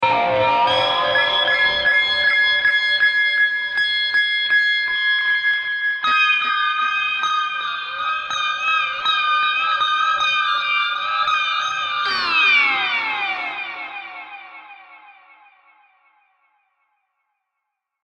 80bpm，6小节。 听起来很吓人:D
标签： 80 bpm Weird Loops Fx Loops 3.03 MB wav Key : Unknown
声道立体声